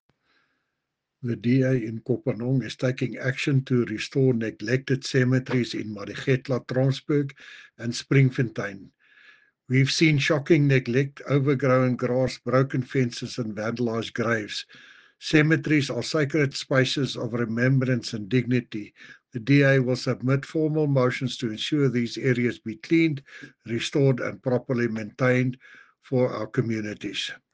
Afrikaans soundbites by Cllr Jacques van Rensburg and Sesotho soundbite by Cllr Kabelo Moreeng.